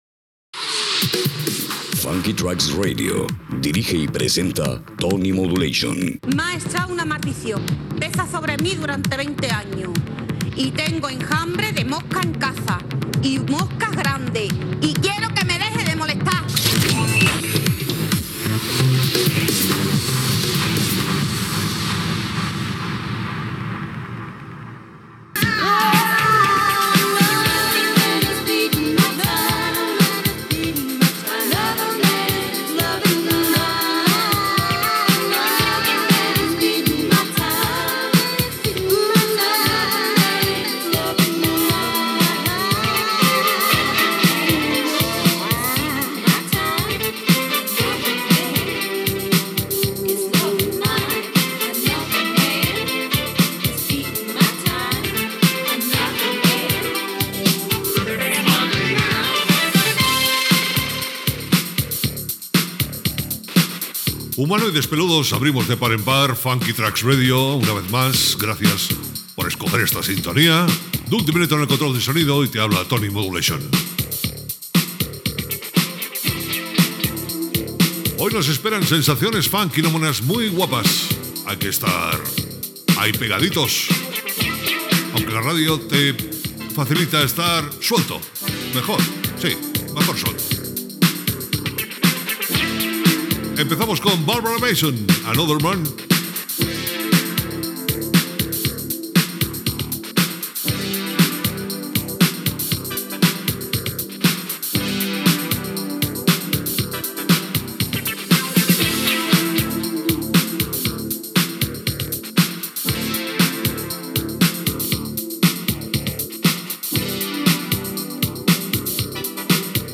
Indicatiu del programa, tema musical, presentació
Musical